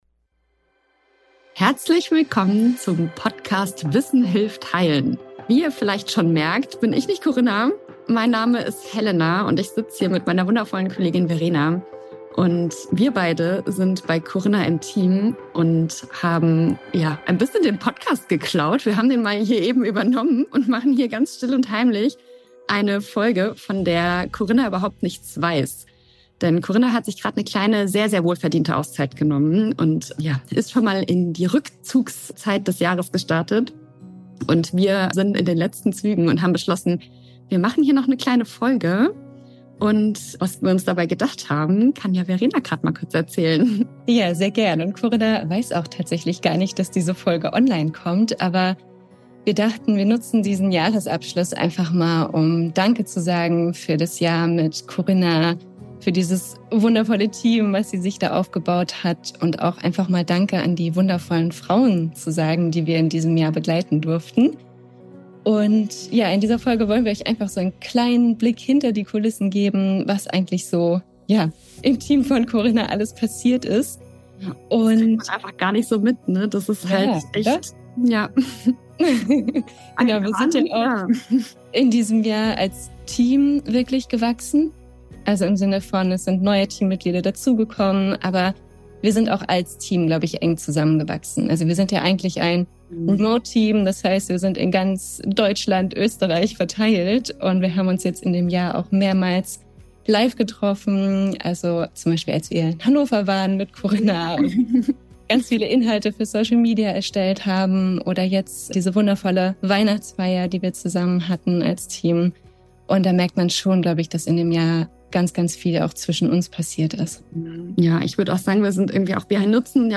Außerdem haben wir wundervolle Frauen aus der Community gefragt, wie sie ihren Jahreswechsel gestalten und welche Rituale sie haben.